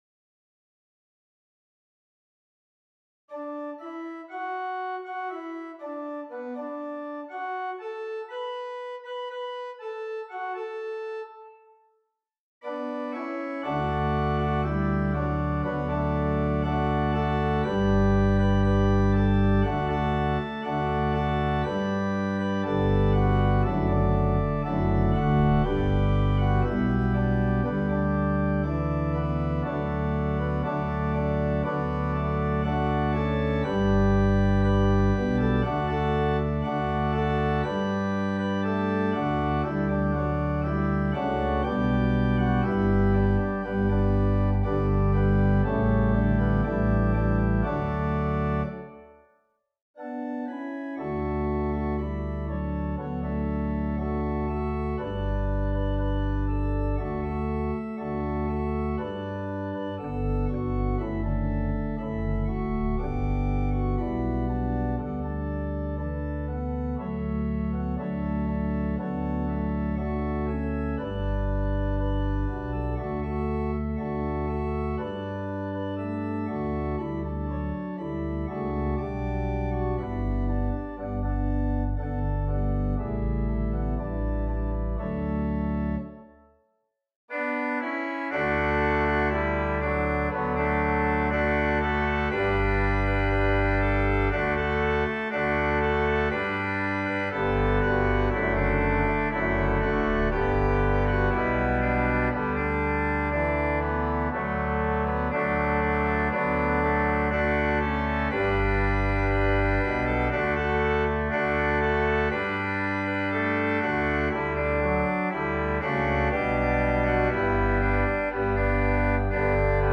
Traditional Scottish
Organ: Little Waldingfield